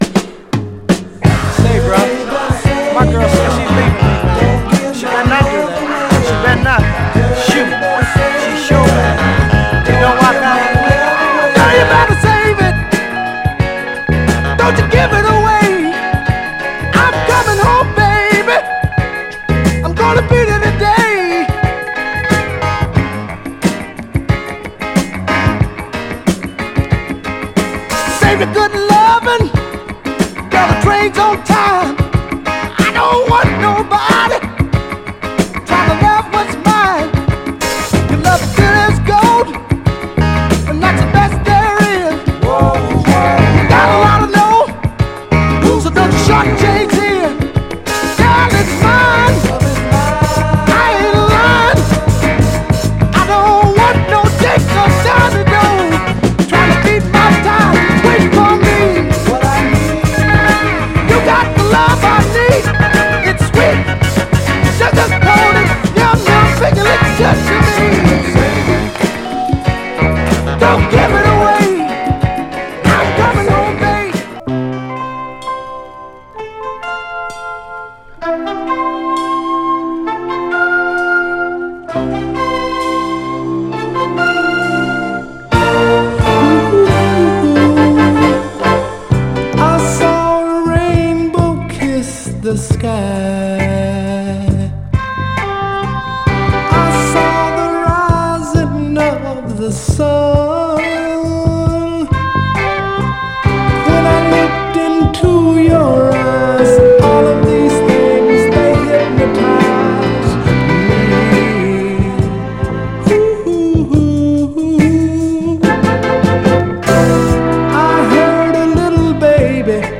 トロトロ甘茶なスウィート・ソウル
※試聴音源は実際にお送りする商品から録音したものです※